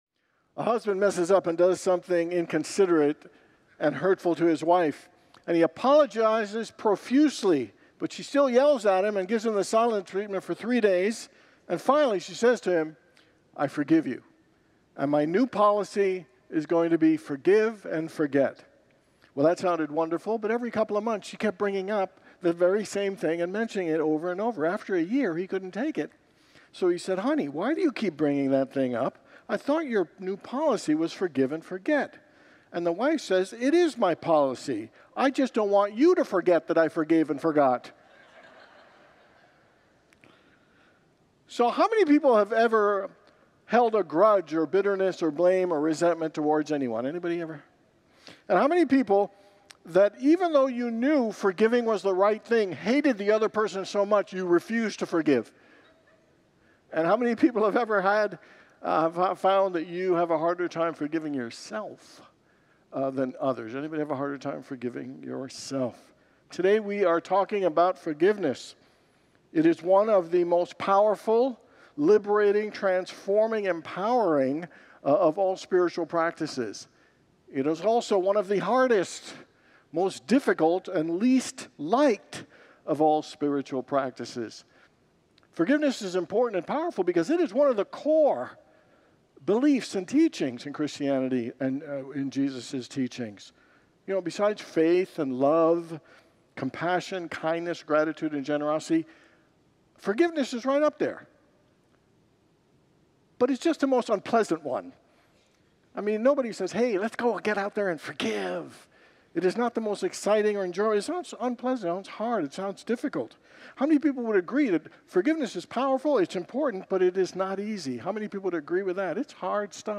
[Congregants laugh] So, how many people have ever held a grudge or bitterness or blame or resentment towards anyone?